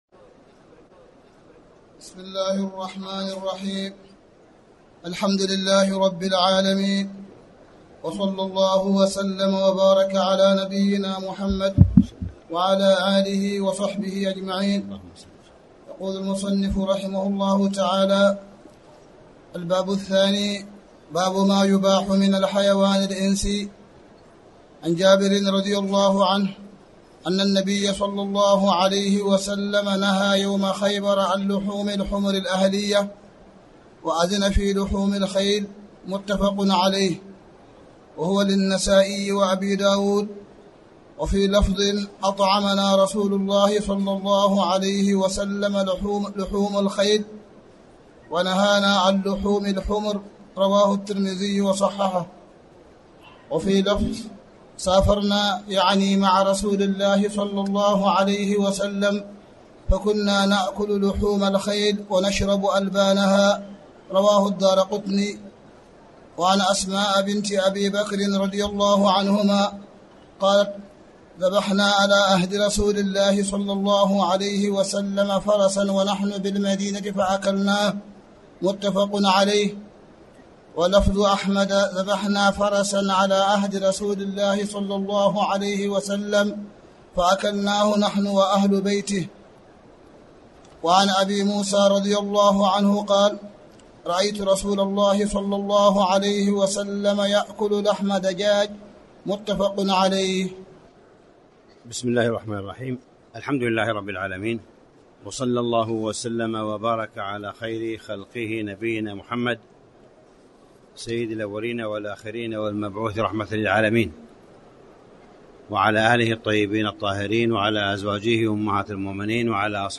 تاريخ النشر ٢٥ ذو القعدة ١٤٣٨ هـ المكان: المسجد الحرام الشيخ: معالي الشيخ أ.د. صالح بن عبدالله بن حميد معالي الشيخ أ.د. صالح بن عبدالله بن حميد باب ما يباح من الحيوان